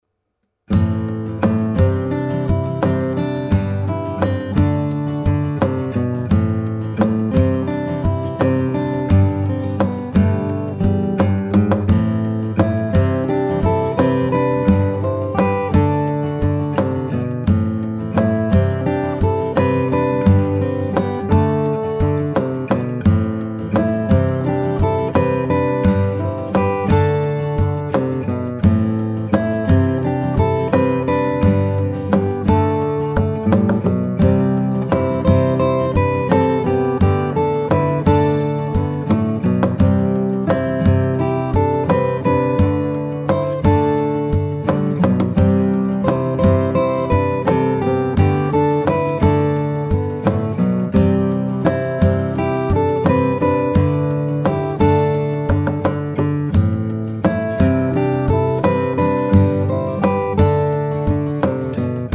Guitar Percussion & Groove Ensemble mit CD/CD-ROM